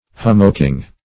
Search Result for " hummocking" : The Collaborative International Dictionary of English v.0.48: Hummocking \Hum"mock*ing\, n. The process of forming hummocks in the collision of Arctic ice.